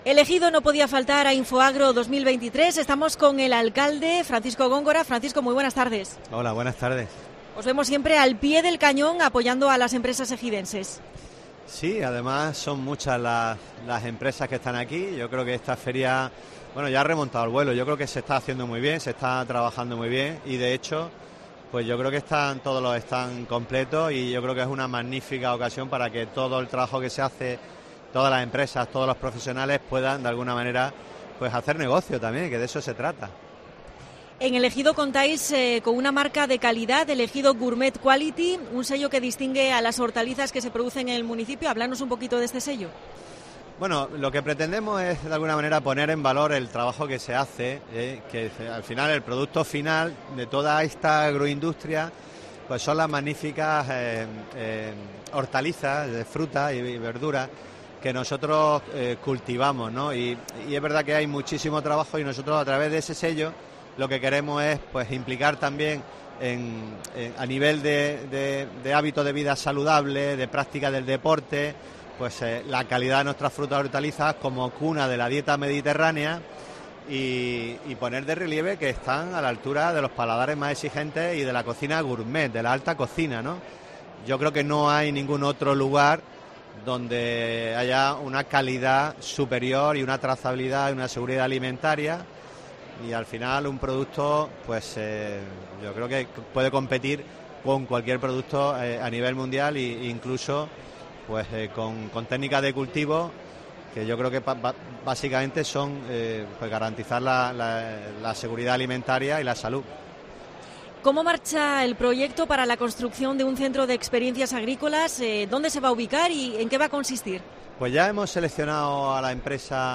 AUDIO: Entrevista en Infoagro al alcalde de El Ejido (Paco Góngora).